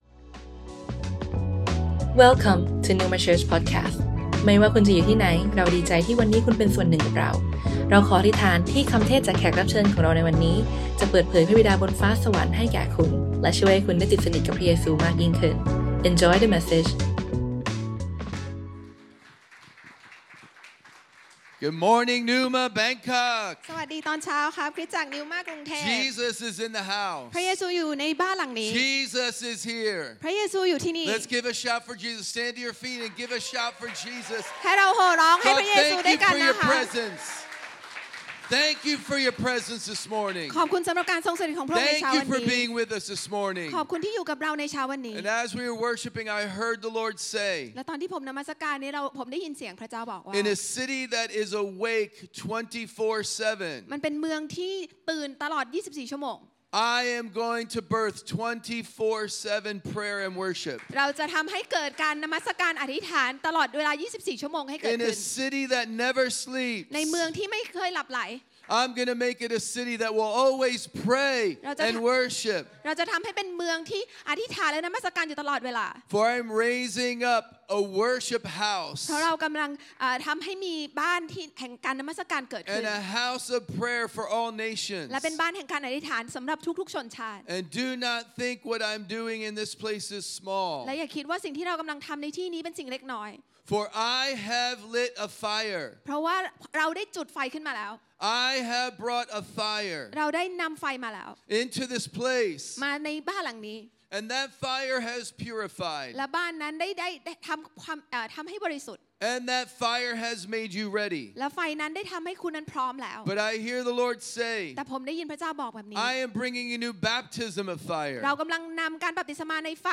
Originally recorded on Sunday 14th July 2024, at Neuma Bangkok.